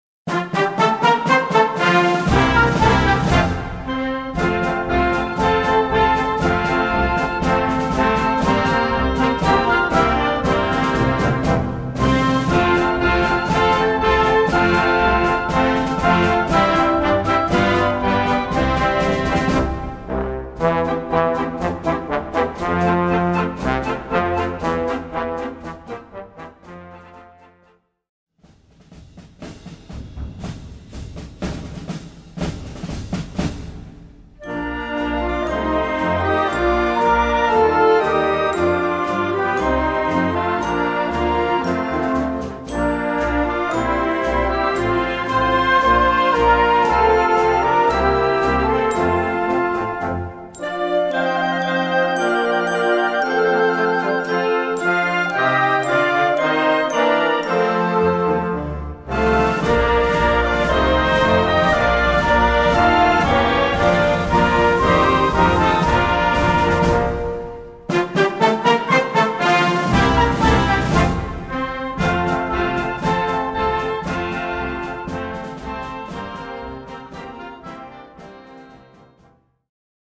Besetzung: Blasorchester
kleinen, unkomplizierten Marsch für Blasorchester